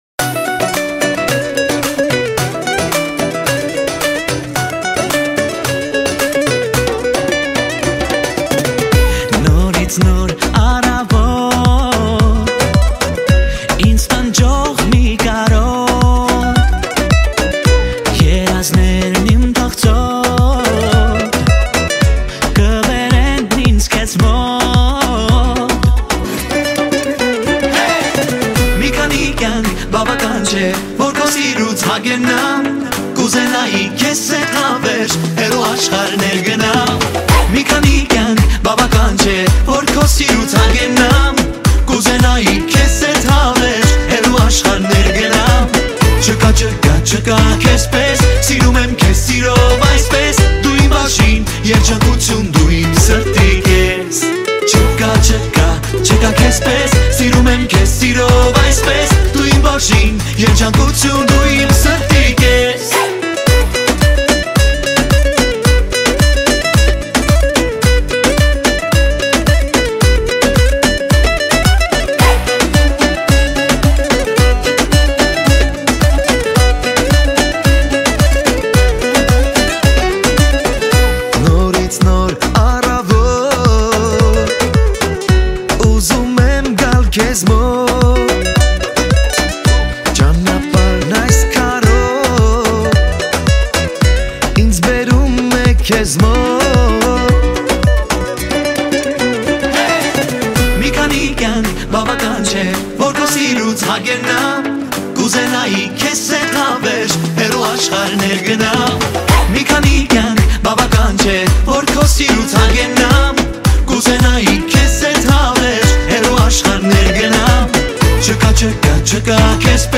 Армянская музыка, Erger 2020, Дуэт
Армянский